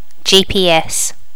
Additional sounds, some clean up but still need to do click removal on the majority.
gps.wav